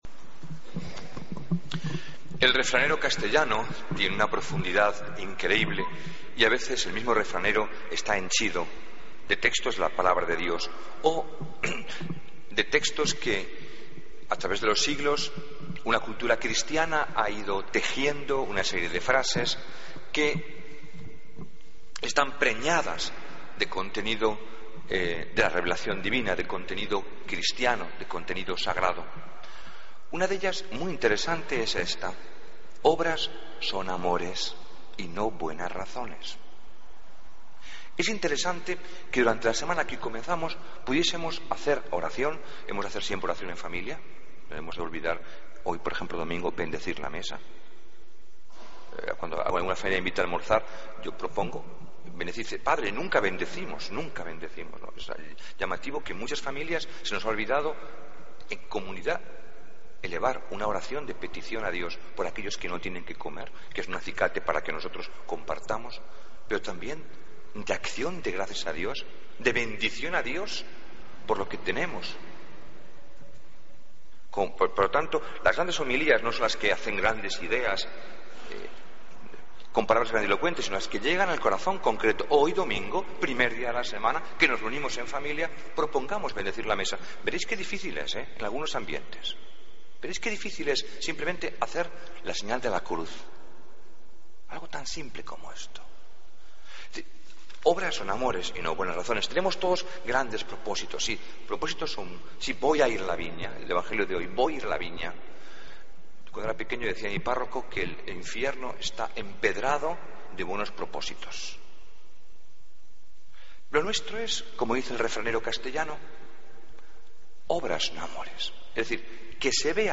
Homilía del Domingo 28 de Septiembre de 2014